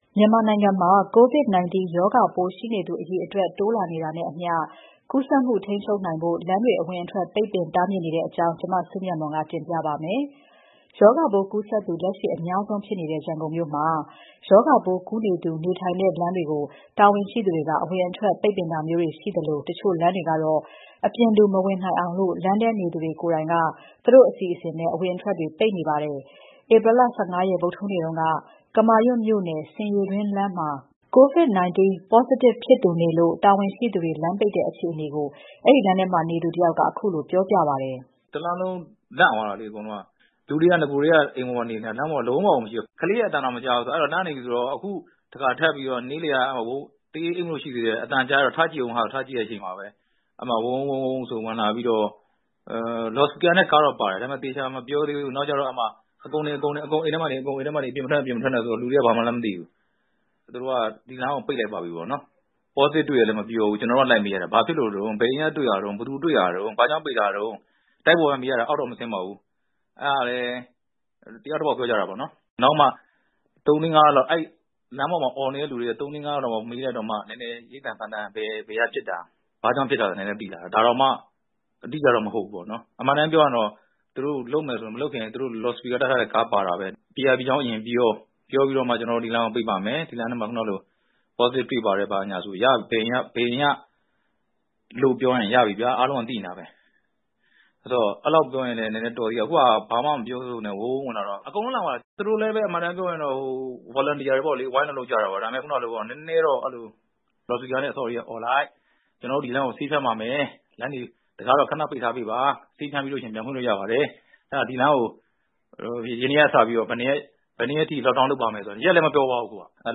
ဧပြီ ၁၅ ရက် ဗုဒ္မဟူးနေ့က ကမာရွတ်မြို့နယ် ဆင်ရေတွင်းလမ်းမှာ COVID-19 positive ဖြစ်သူနေလို့ တာဝန်ရှိ သူတွေ လမ်းပိတ်တဲ့အခြေအနေကို အဲဒီလမ်းထဲနေသူတယောက်က ခုလိုပြောပြပါတယ်။